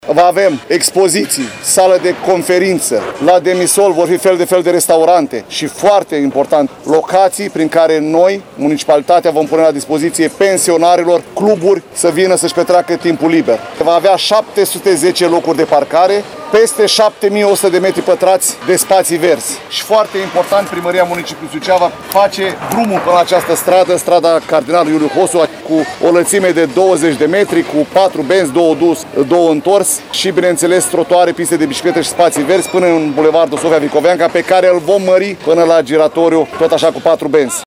La rândul său, viceprimarul LUCIAN HARȘOVSCHI a detaliat celelalte dotări ale viitoarei săli polivalente din Suceava.